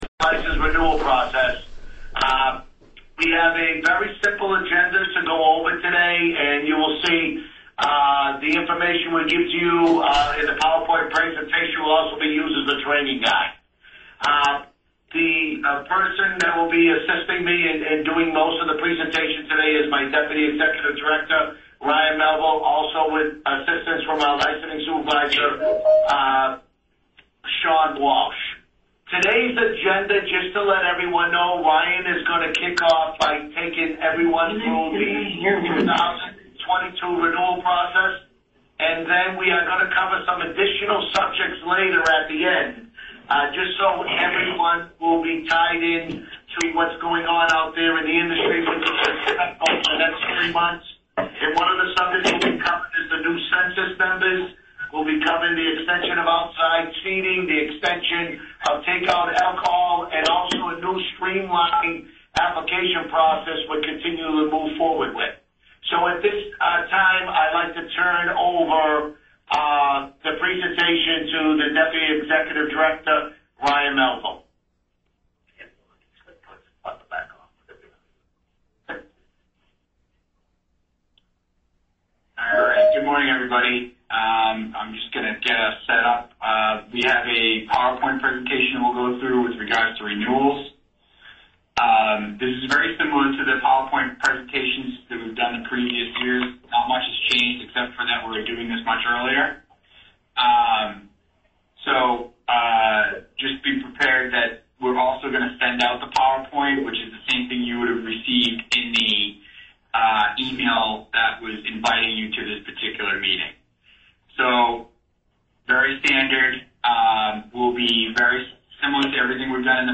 2022 Retail License Renewals Meeting with LLAs (Audio)